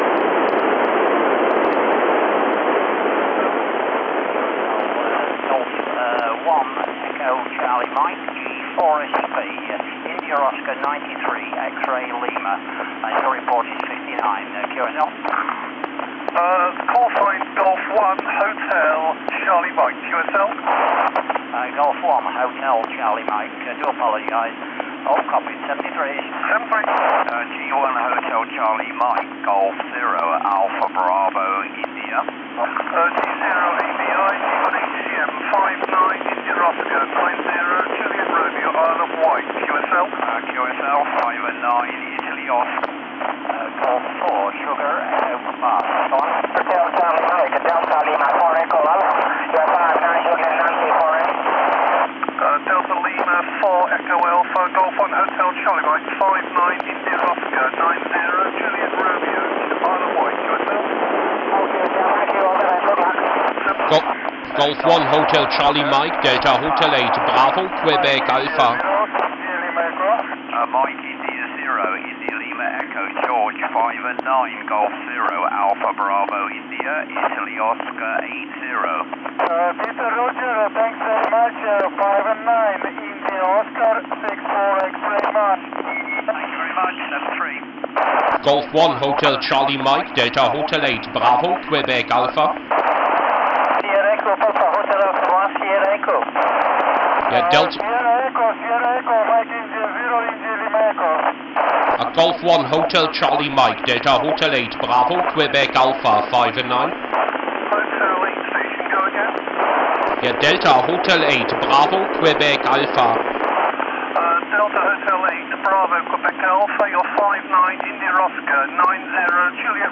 Stereo audio, left channel is satellite RX, right channel local audio.
Quite a good pass of AO-91/Fox-1C: